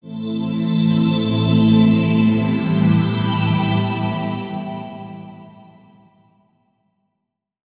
SYNTHPAD014_PROGR_125_A_SC3.wav